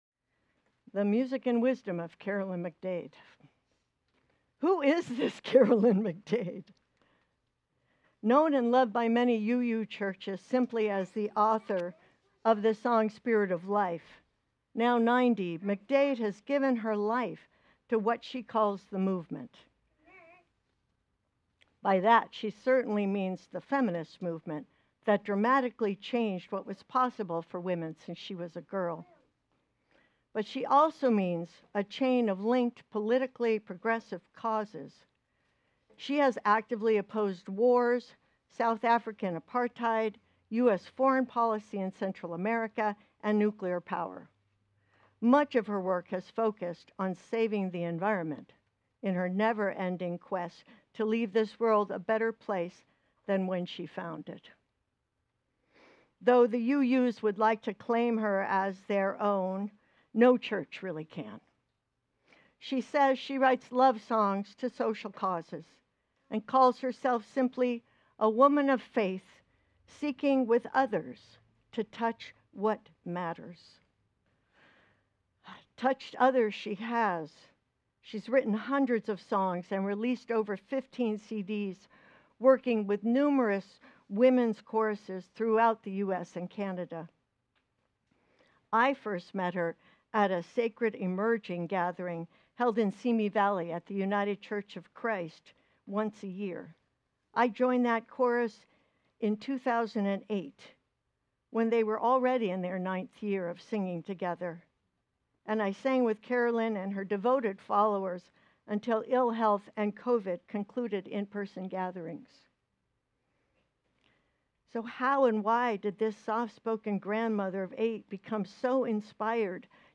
Join us for a musical celebration of the life and wisdom of Singer-songwriter, Social Justice and Environmental Activist, Carolyn McDade.